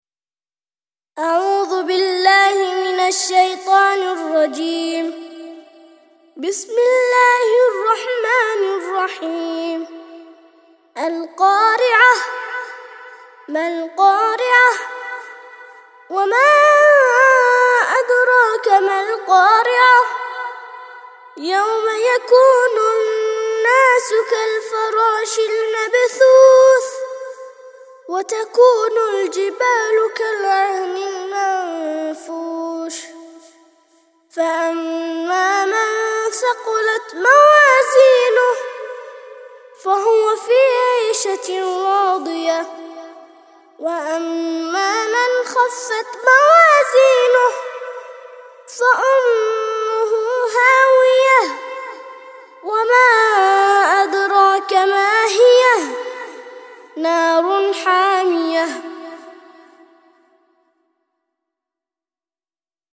101- سورة القارعة - ترتيل سورة القارعة للأطفال لحفظ الملف في مجلد خاص اضغط بالزر الأيمن هنا ثم اختر (حفظ الهدف باسم - Save Target As) واختر المكان المناسب